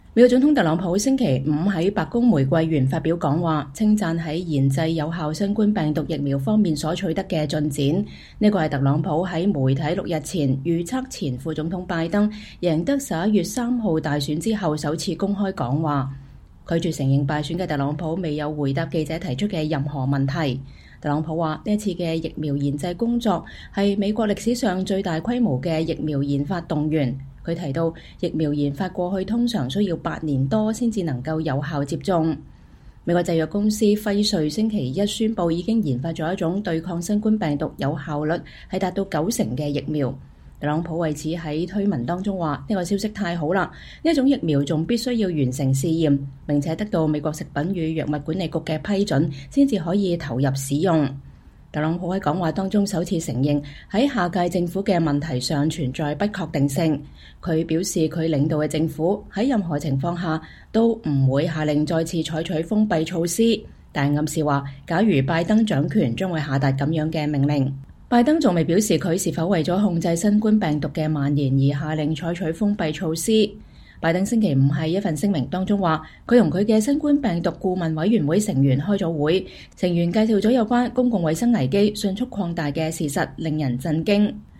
特朗普總統白宮玫瑰園講話 稱讚疫苗研製取得的進展
美國總統特朗普星期五(11月13日)在白宮玫瑰園發表講話，稱讚在研製有效新冠病毒疫苗方面所取得的進展。這是特朗普在媒體六天前預測前副總統拜登贏得11月3日大選後首次公開講話。